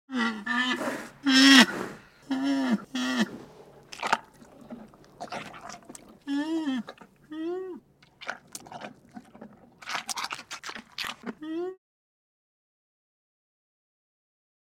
جلوه های صوتی
دانلود صدای کرگدن 2 از ساعد نیوز با لینک مستقیم و کیفیت بالا
برچسب: دانلود آهنگ های افکت صوتی انسان و موجودات زنده دانلود آلبوم صدای حیوانات وحشی از افکت صوتی انسان و موجودات زنده